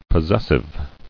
[pos·ses·sive]